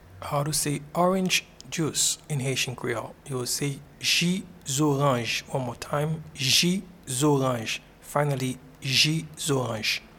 Pronunciation and Transcript:
Orange-juice-in-Haitian-Creole-Ji-zoranj.mp3